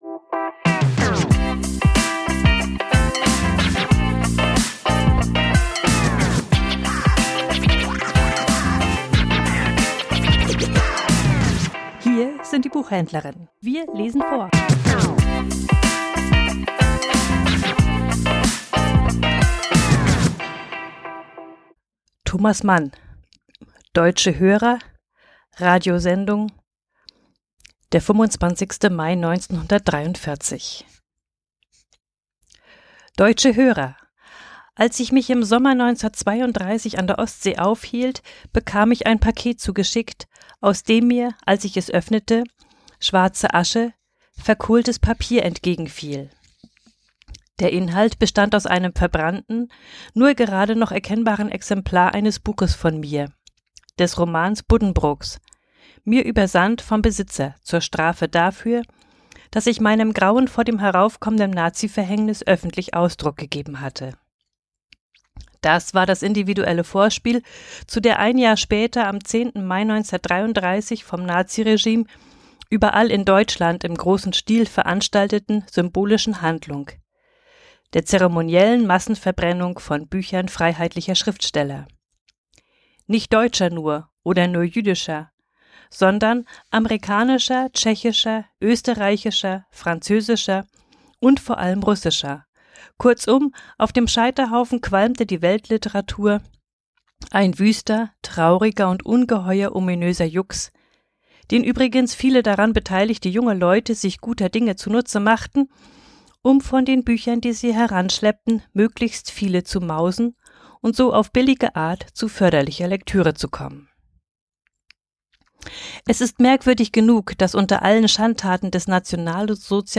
Vorgelesen: Deutsche Hörer - 25 Mai 1943